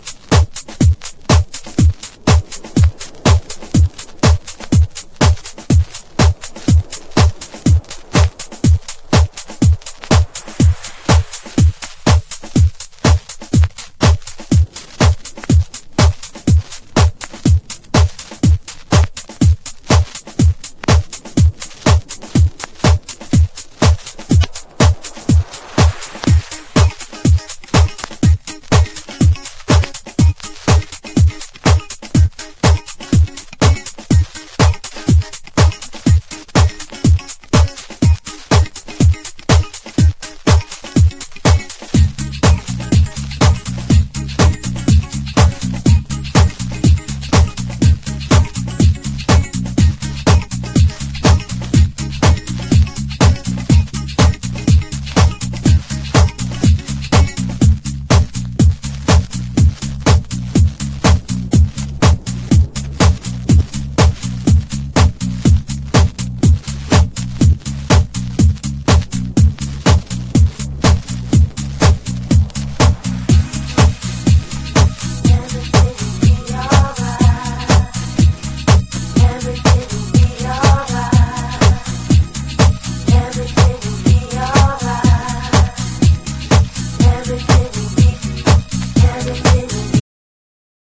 • HOUSE
所によりノイズありますが、リスニング用としては問題く、中古盤として標準的なコンディション。